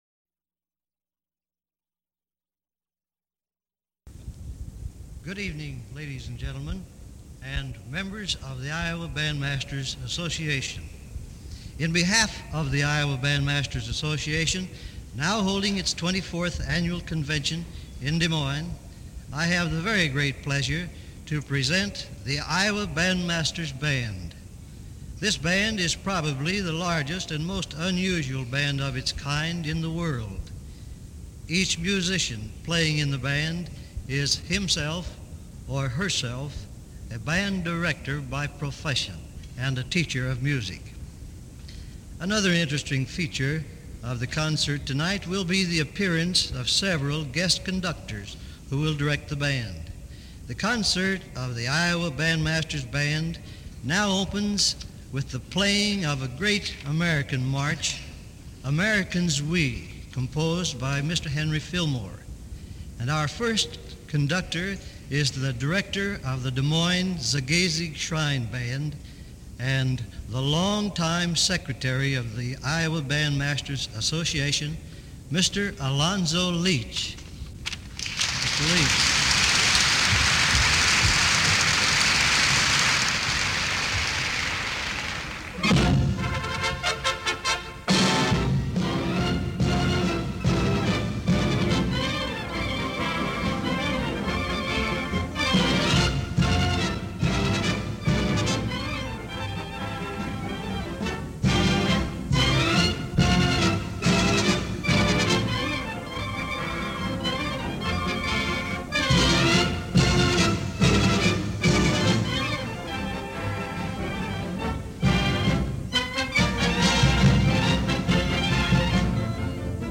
These mp3 files are from a CD that was a digital transfer from a reel-to-reel tape. It is a recording of radio WHO’s broadcast of the Iowa Bandmasters Association “Directors Band” that played at the 1951 convention in Des Moines.